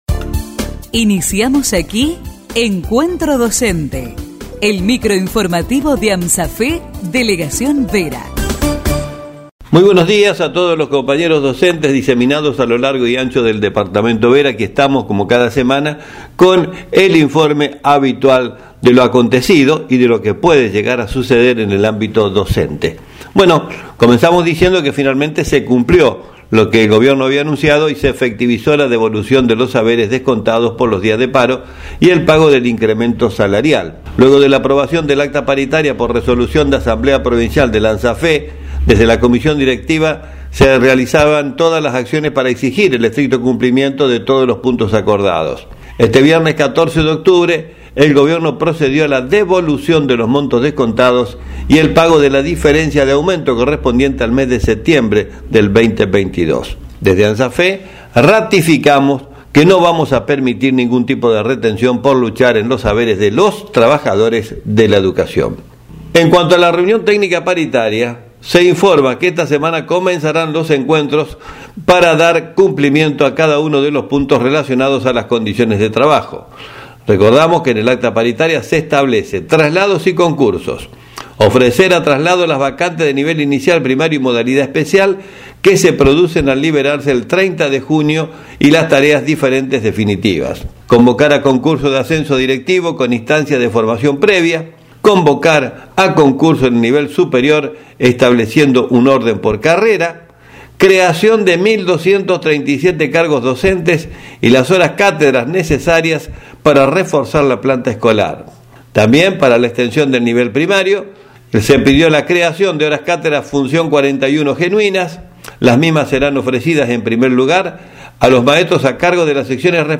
Micro informativo de AMSAFE Vera.